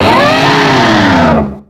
Cri de Mammochon dans Pokémon X et Y.